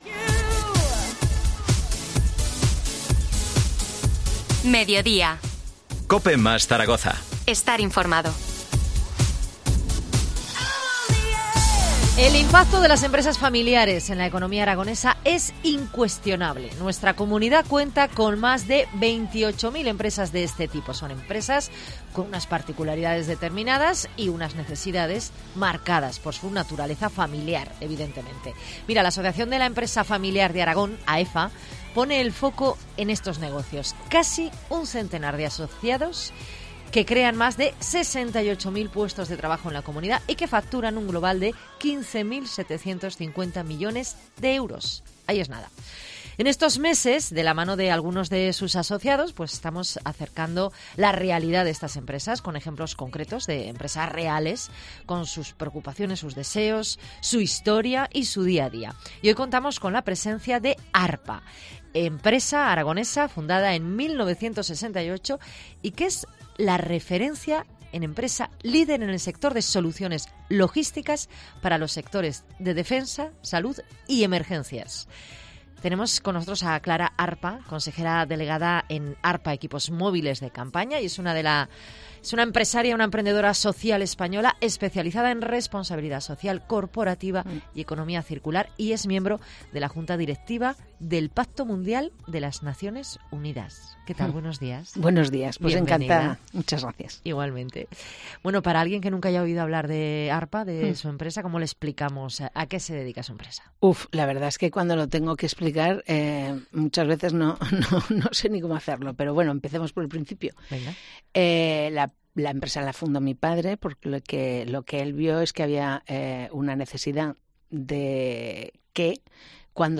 AUDIO: Entrevista AEFA